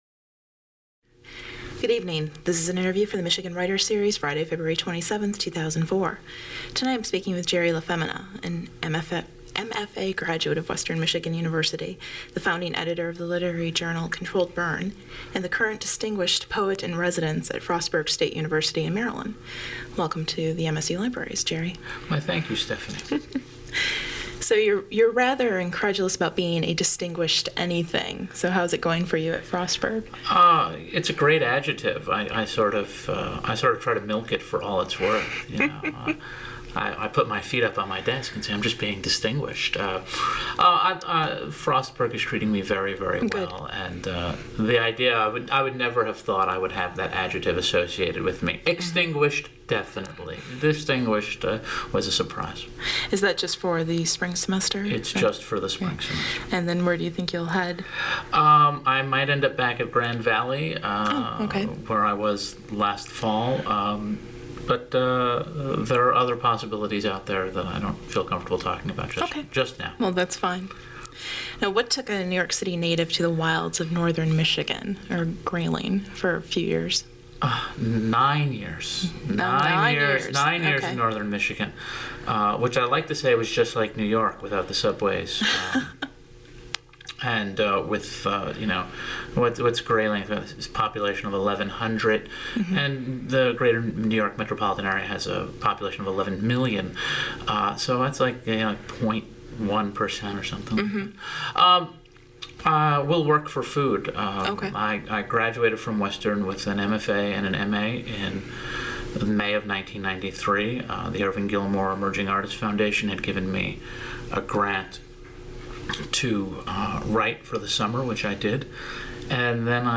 Held in the MSU Main Library.